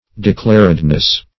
Declaredness \De*clar"ed*ness\, n. The state of being declared.
declaredness.mp3